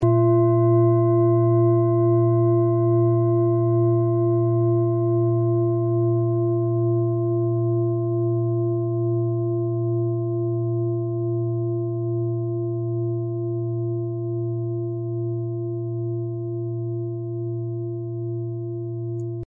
Klangschalen-Typ: Bengalen
Klangschale Nr.2
(Aufgenommen mit dem Filzklöppel/Gummischlegel)
klangschale-set-2-2.mp3